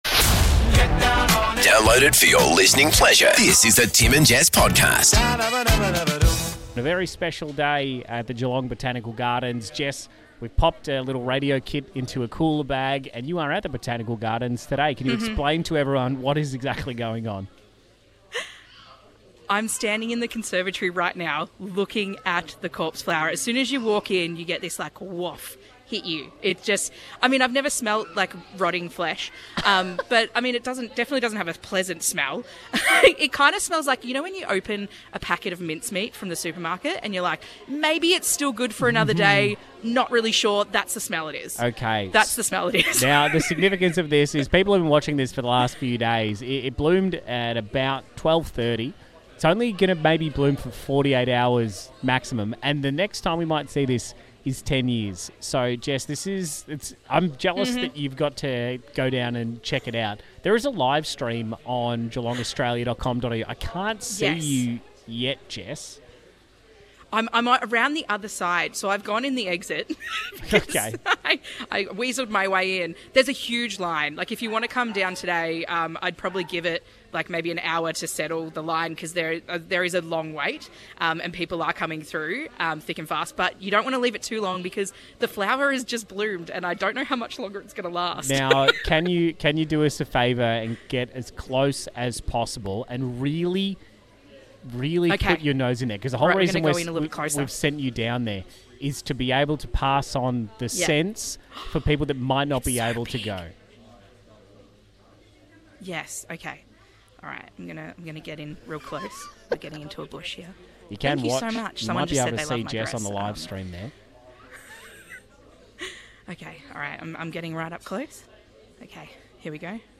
LIVE at the Botanical Gardens for a once in 10 year event! - Exam Cram: Chemistry & History Revolutions - How long have you 'borrowed' something?